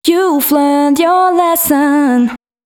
014 female.wav